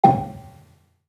Gamelan / Gambang
Gambang-G4-f.wav